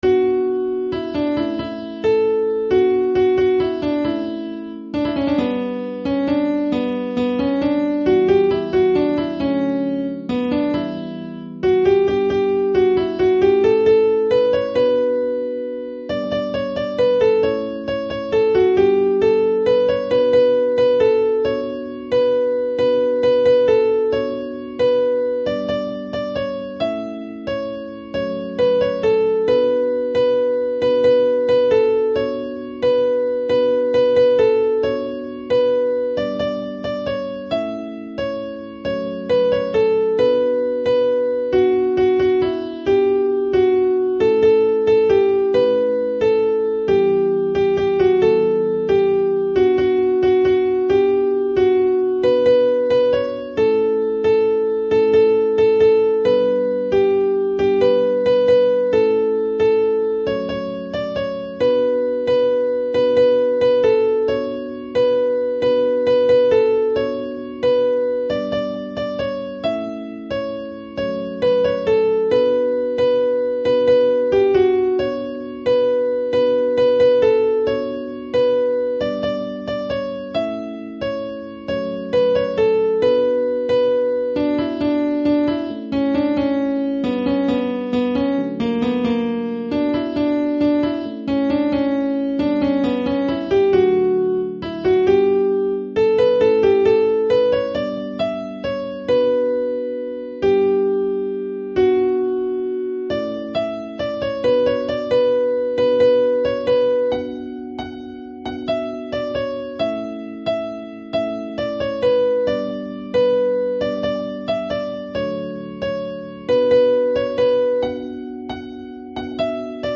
ساز: کیبورد